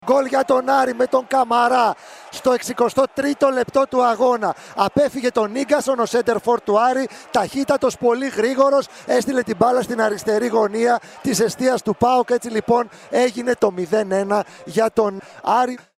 ΕΡΑΣΠΟΡ: Η περιγραφή των γκολ της 8ης αγωνιστικής των πλέι οφ (audio)